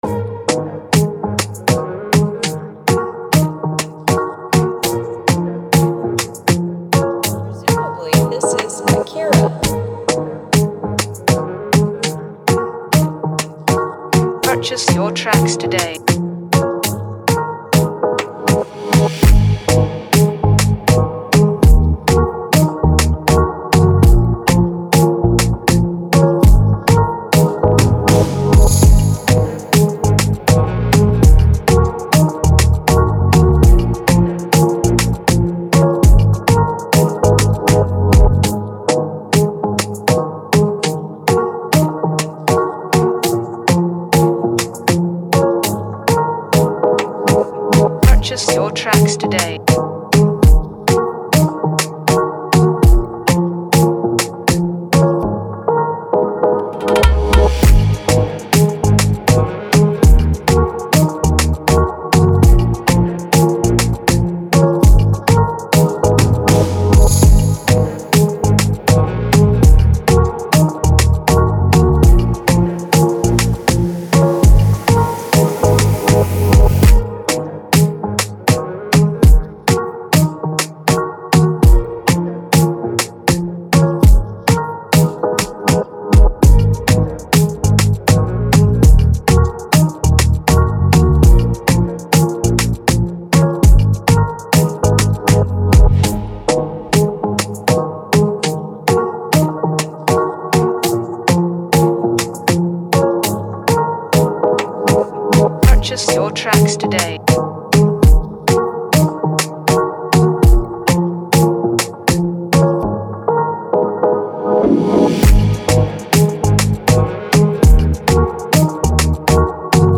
offering a dynamic Gyration bouncy beat.